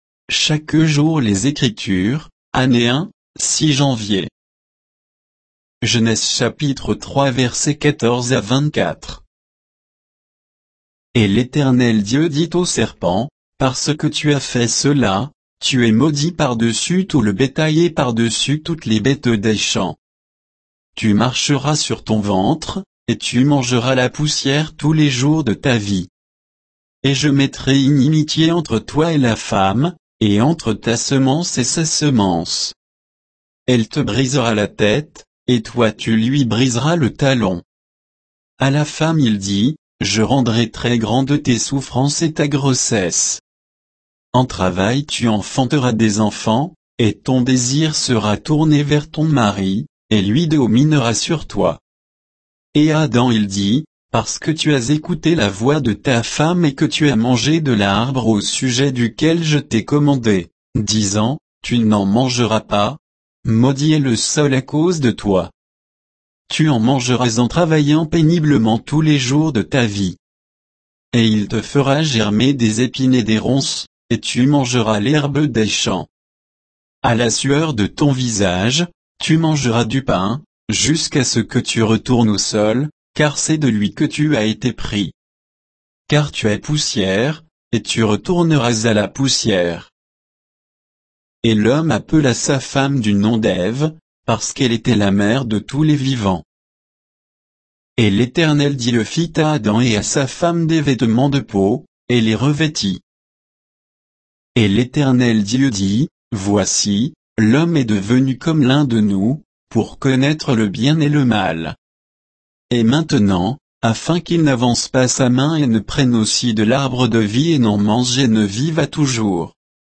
Méditation quoditienne de Chaque jour les Écritures sur Genèse 3, 14 à 24